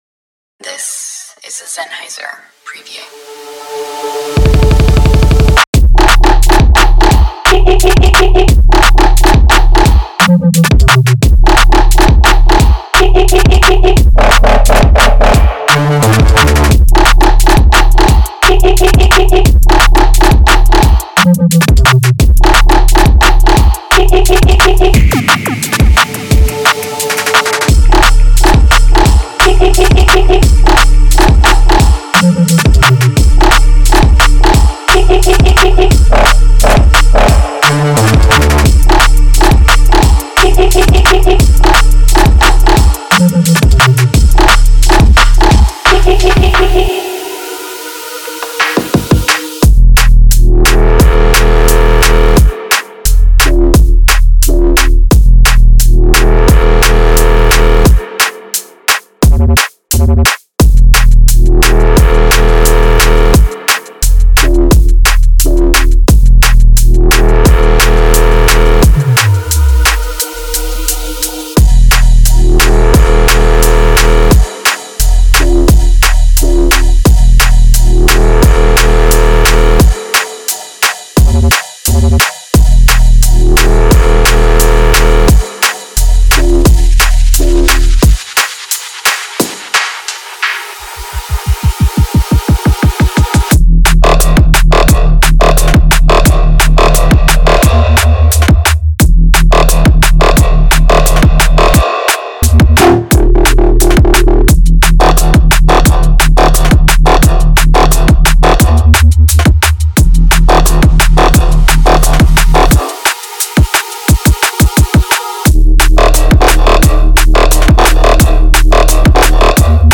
このパックはややダークな領域にぴったりで、グリット感のある揺れるサウンドと脈打つグルーヴが特徴です。
その生々しく研磨されていないテクスチャーは、最高のジャンプアップやローラーズに最適です。
リズムはスナッピーなものからローリングするものまで多彩で、さまざまなドラムループに対応します。
全てはパックの重厚感あるベースシンセループによって支えられています。
デモサウンドはコチラ↓
Genre:Drum and Bass